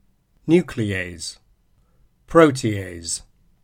Not included in the above are specialized words referring to enzymes, such as nuclease and protease, which are trisyllabic and end in /z/: nu-cle-ase, pro-te-ase:
nuclease_protease.mp3